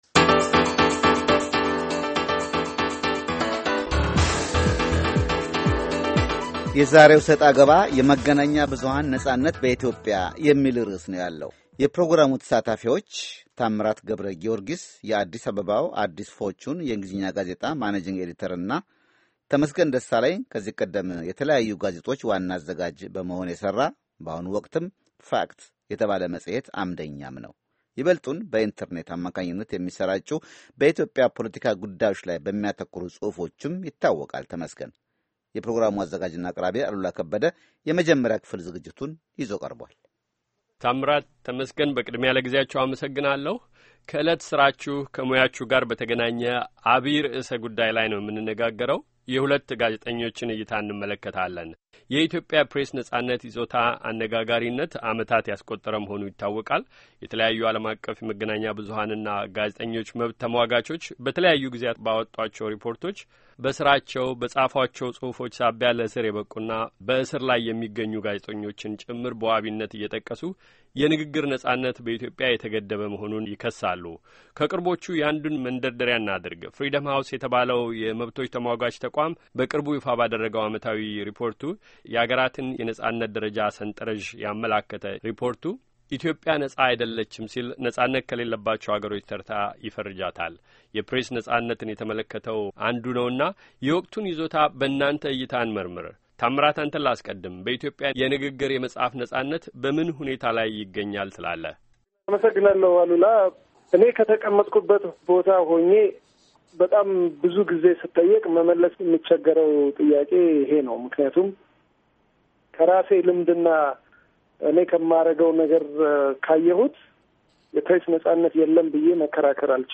ክርክር፥ የኢትዮጵያ የመገናኛ ብዙኃንና የንግግር ነጻነት ይዞታ፤